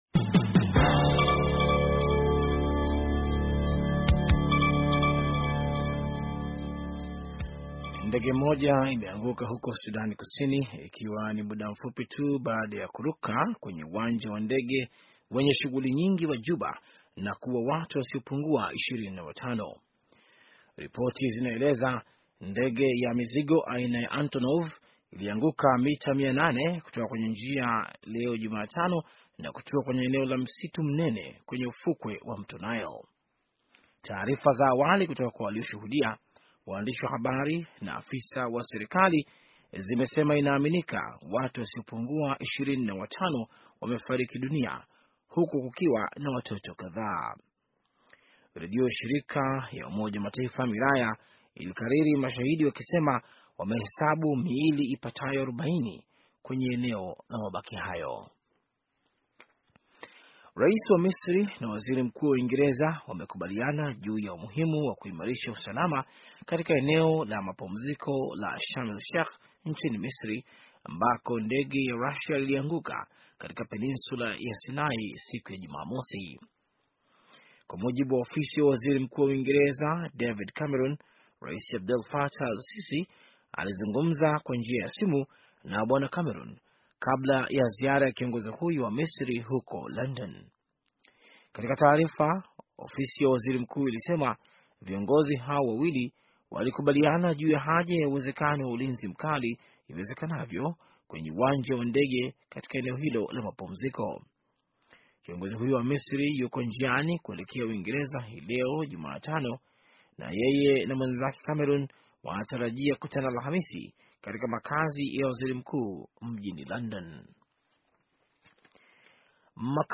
Taarifa ya habari - 4:35